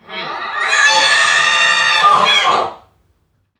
NPC_Creatures_Vocalisations_Robothead [76].wav